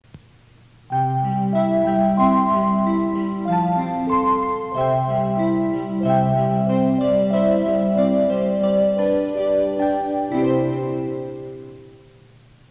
２３０００系「伊勢志摩ライナー」と、２１０００系・２１０２０系「アーバンライナー」の自動放送では“各駅到着前始発駅発車前に違う車内チャイム”が流れます。